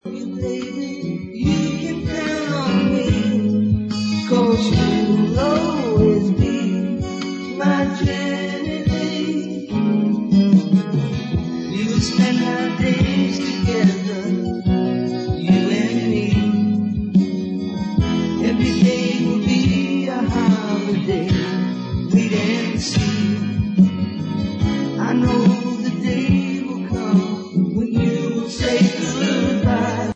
lead vocals, drums, percussion
(slide and acoustic guitars)
piano, keyboards